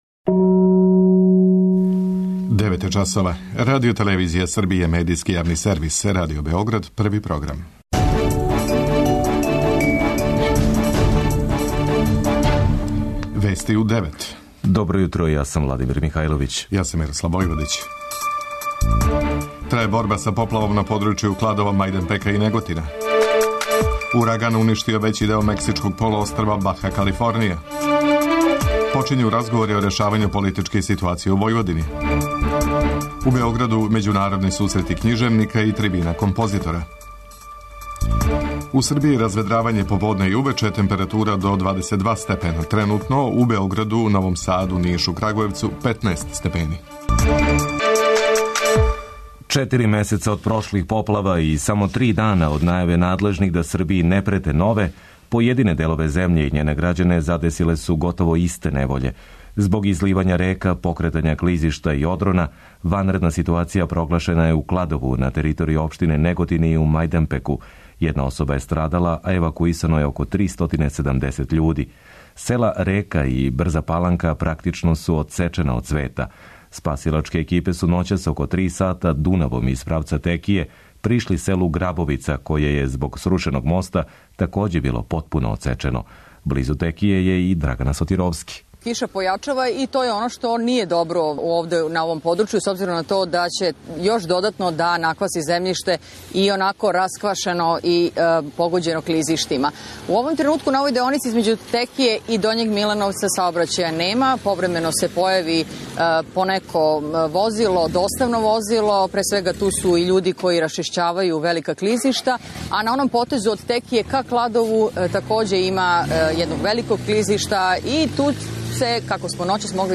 преузми : 10.28 MB Вести у 9 Autor: разни аутори Преглед најважнијиx информација из земље из света.